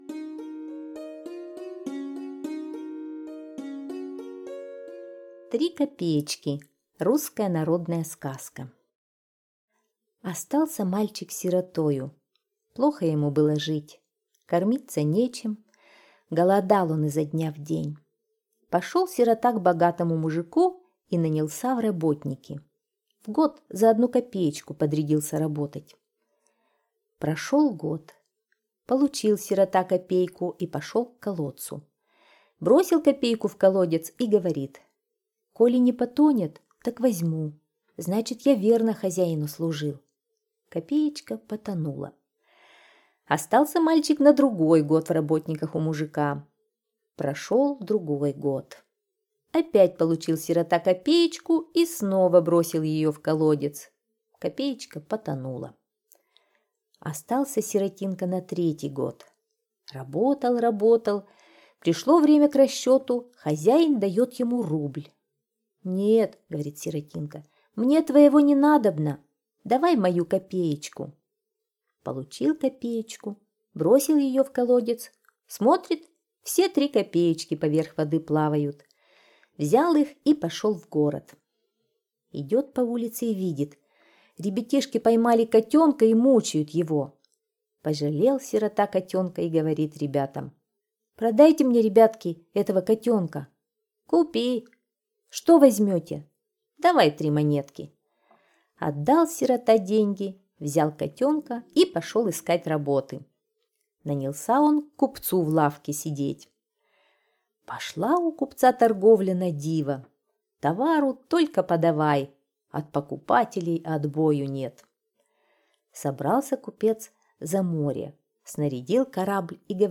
Три копеечки - русская народная аудиосказка - слушать онлайн